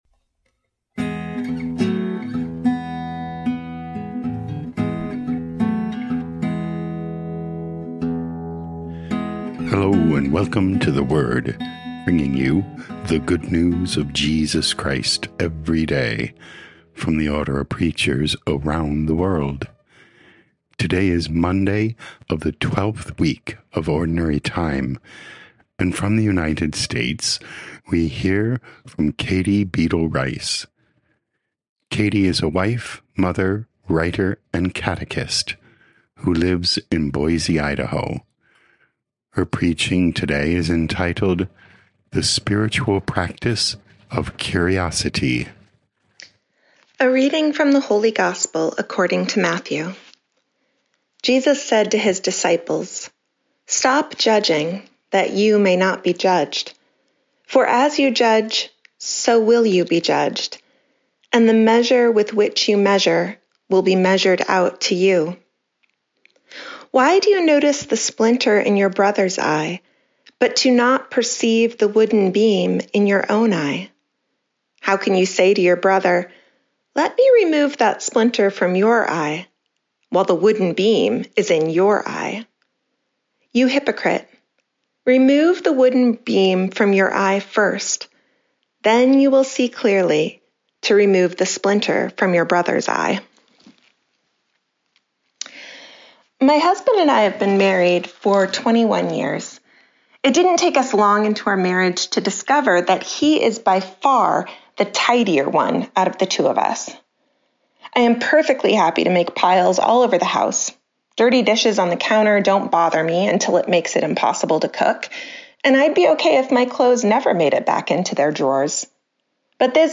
23 Jun 2025 The Spiritual Practice of Curiosity Podcast: Play in new window | Download For 23 June 2025, Monday of week 12 in Ordinary Time, based on Matthew 7:1-5, Genesis 12:1-9, sent in from Boise, Idaho.
Preaching